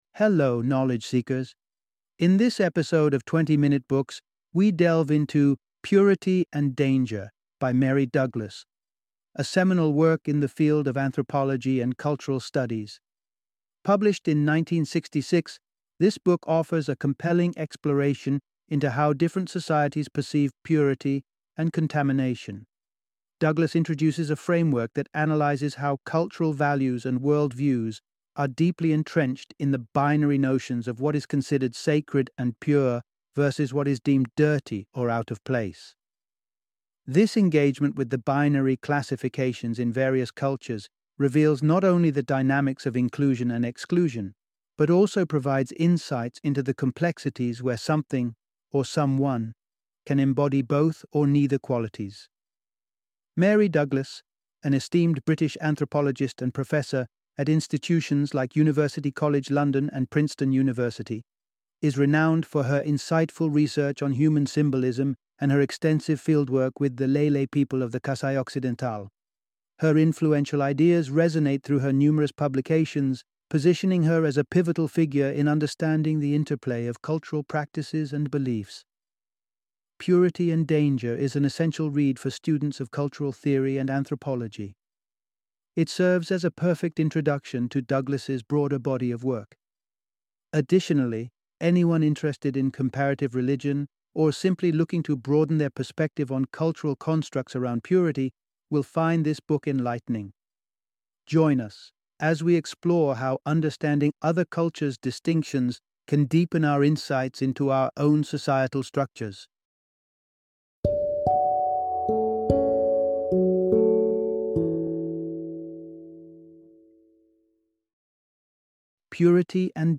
Purity and Danger - Audiobook Summary